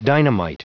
Prononciation du mot dynamite en anglais (fichier audio)
Prononciation du mot : dynamite